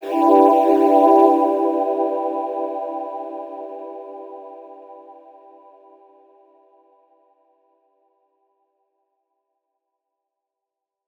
Chords_E_02.wav